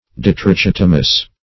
Search Result for " ditrichotomous" : The Collaborative International Dictionary of English v.0.48: Ditrichotomous \Di`tri*chot"o*mous\, a. [Pref. di- + trichotomous.] 1.